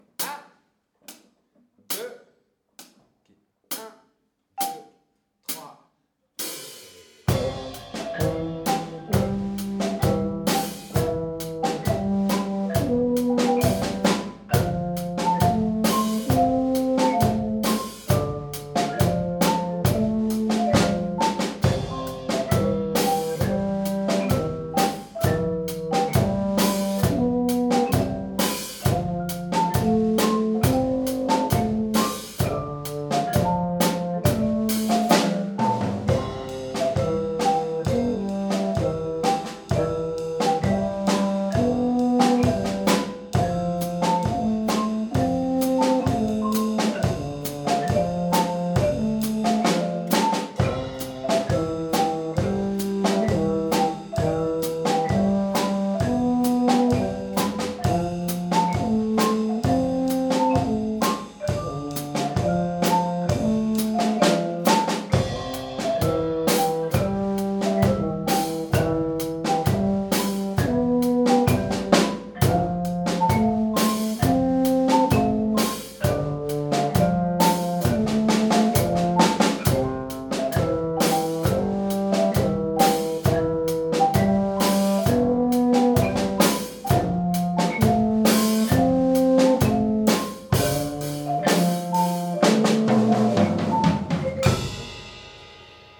L'accompagnement audio de votre compo
Œuvre de référence : Le Blues - Sweet Home Chicago